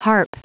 Prononciation du mot harp en anglais (fichier audio)
Prononciation du mot : harp